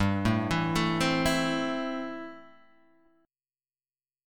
C6/G chord